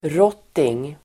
Ladda ner uttalet
rotting substantiv, rattan , cane Uttal: [²r'åt:ing]